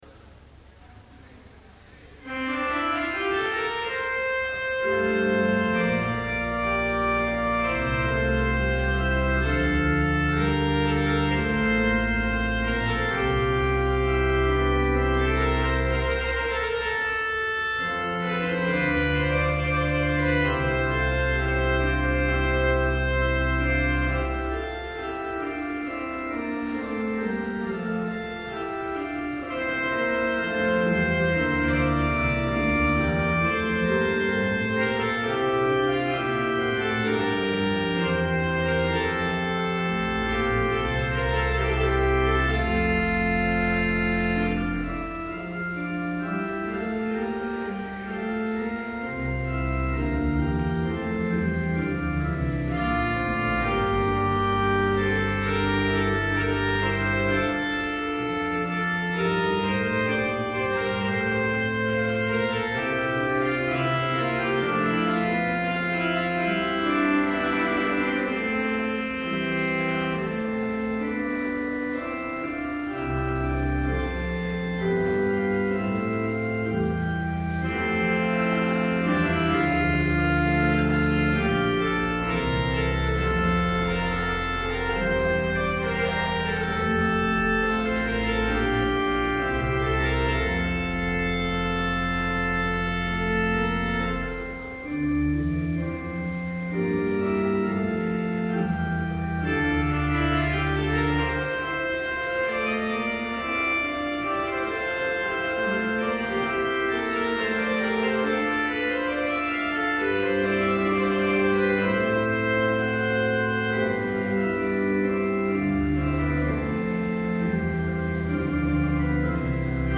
Complete service audio for Chapel - May 10, 2012